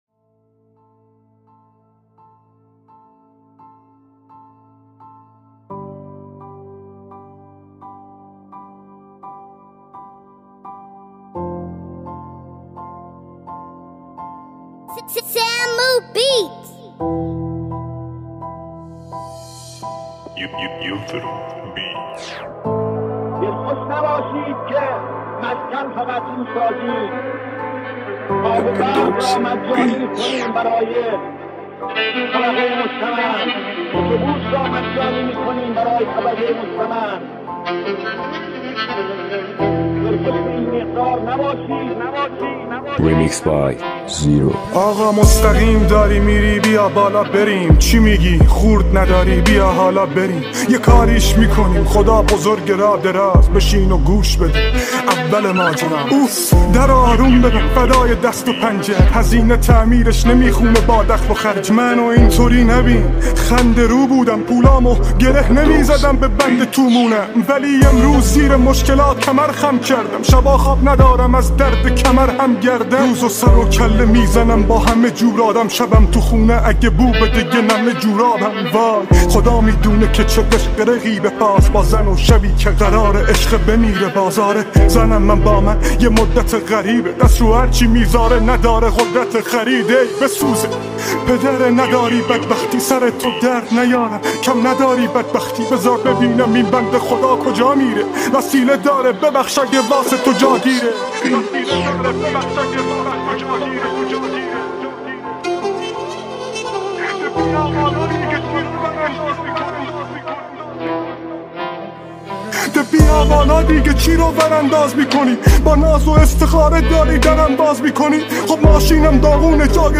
دانلود ریمیکس رپ فارسی